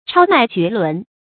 超迈绝伦 chāo mài jué lún
超迈绝伦发音